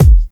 • Prominent Bass Drum Single Hit E Key 115.wav
Royality free kick single shot tuned to the E note. Loudest frequency: 269Hz
prominent-bass-drum-single-hit-e-key-115-0eC.wav